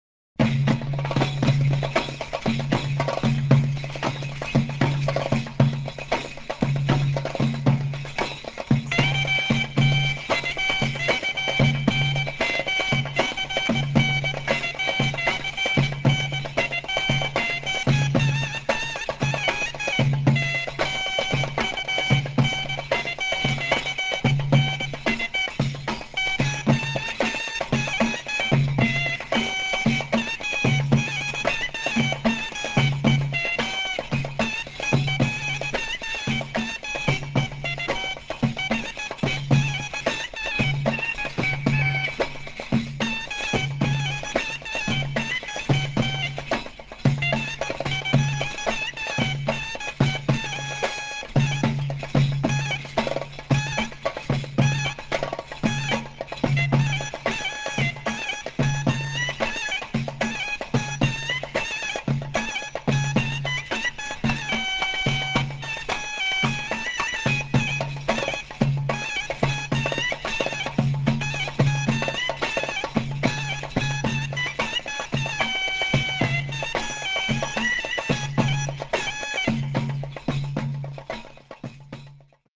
Mizmar with sa'idi rhythm